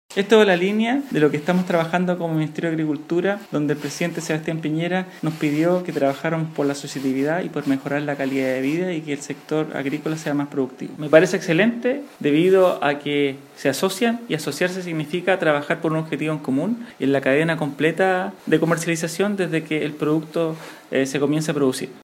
Por su parte el Seremi de Agricultura de la región de Los Lagos, Juan Vicente Barrientos, agregó que el Ministerio de Agricultura está promoviendo la asociatividad fuertemente, ya que les permite a los pequeños productores unir fuerzas, bajar sus costos, vender en mayor volumen y, además, alcanzar mejores resultados.